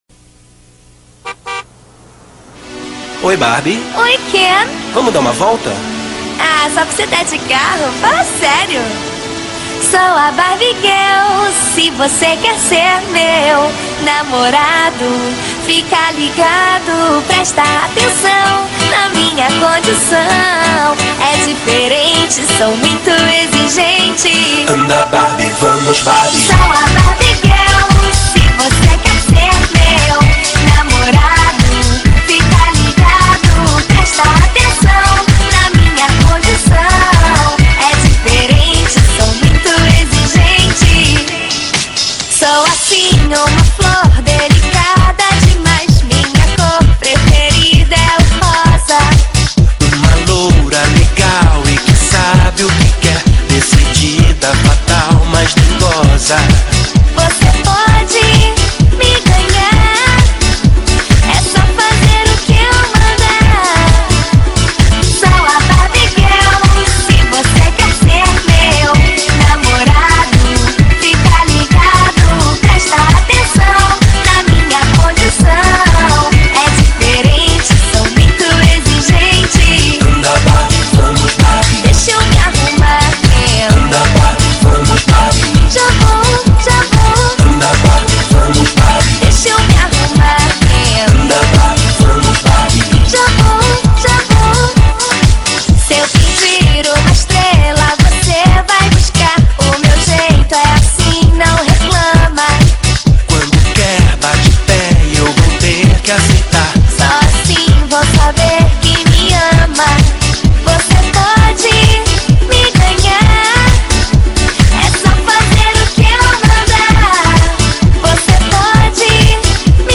2025-01-05 15:39:37 Gênero: MPB Views